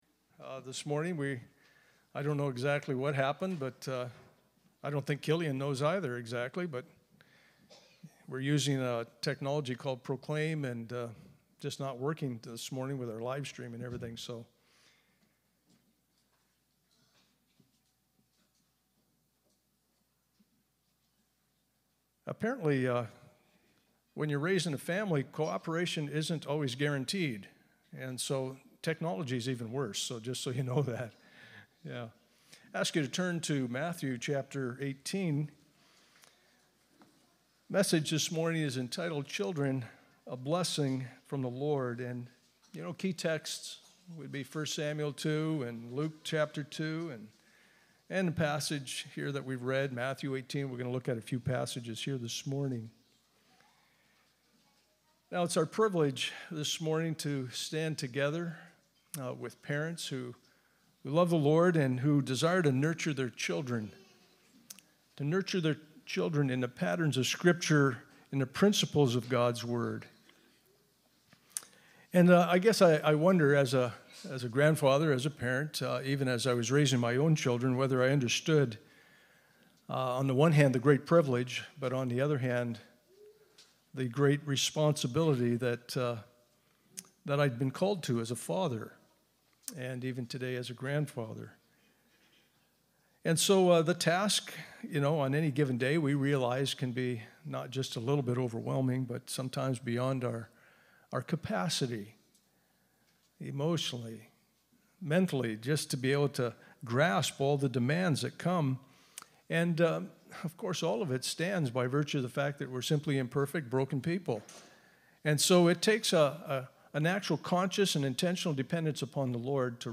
The Blessings of Children – Child Dedication Service